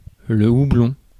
Ääntäminen
Ääntäminen France: IPA: [u.blɔ̃] Tuntematon aksentti: IPA: /hu.blɔ̃/ Haettu sana löytyi näillä lähdekielillä: ranska Käännös Konteksti Ääninäyte Substantiivit 1. hop kasvitiede UK 2. hops Suku: m .